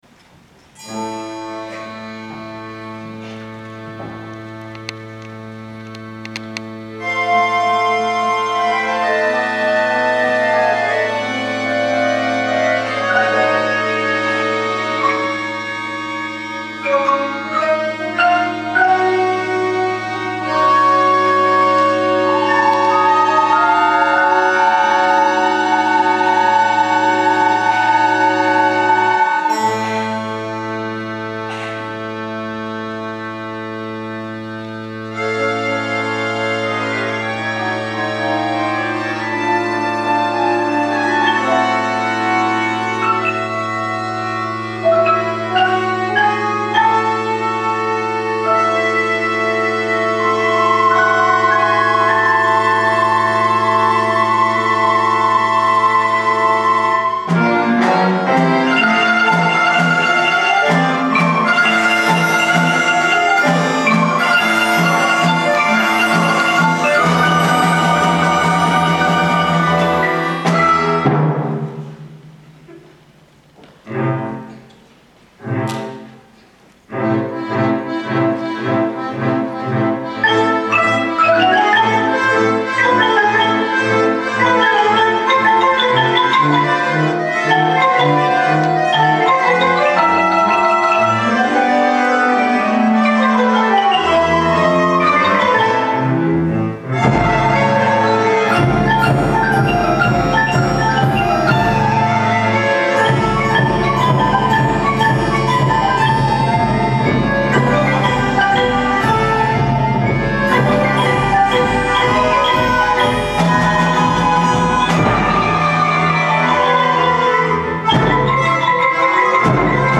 今日は午後からクレオ大阪で大阪市小学校音楽交流会がありました。
合唱「ＨＥＩＷＡの鐘」が始まると、平和への思いを込めた歌声が会場全体に響き渡ります。
合奏では、ひとりひとりが自分らしく表現し、迫力のある音楽をつくります。
演奏が終わると会場中から大きな拍手！